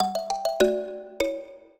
mbira
minuet14-4.wav